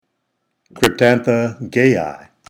Pronunciation/Pronunciación:
Cryp-tán-tha  gày-i